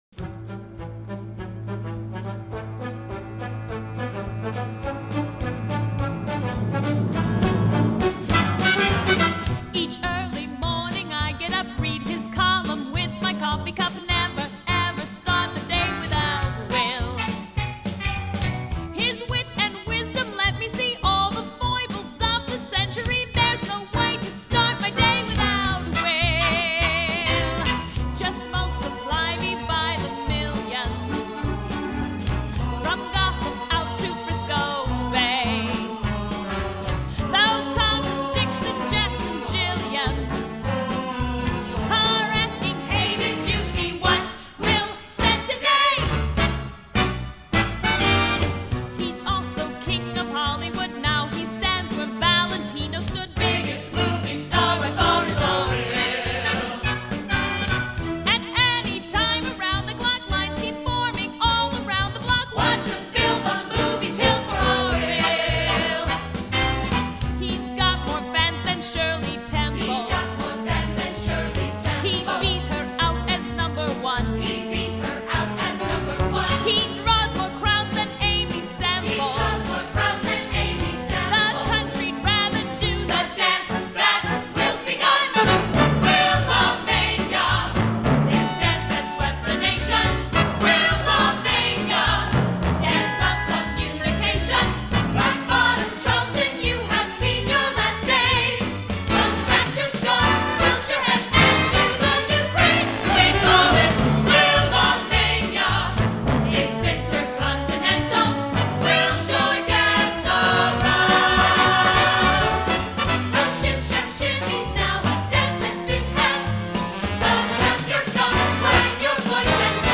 Its syncopation puts me on the move.
Genre: Musical